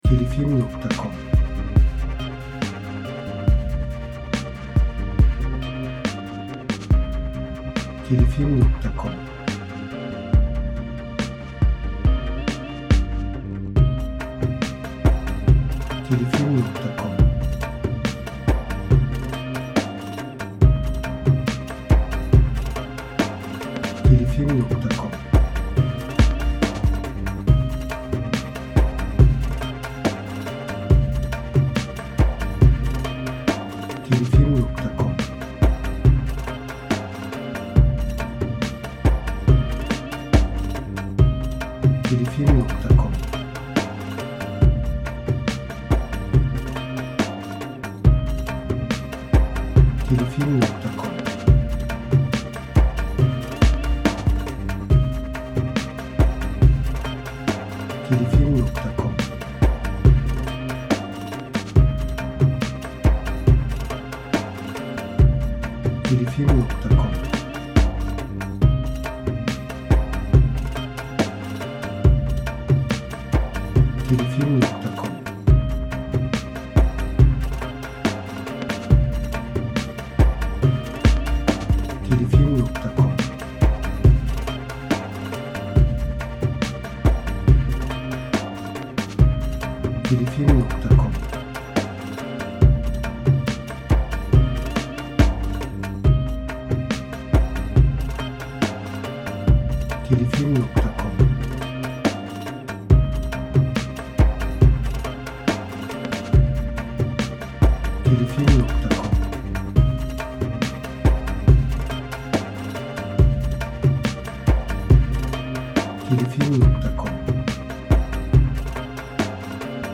Eser Türü : Müzikal Tema Eser Tipi : Enstrümental